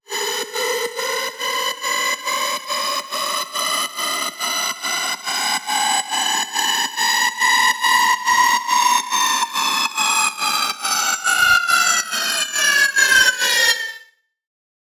SOUTHSIDE_fx_all_rise.wav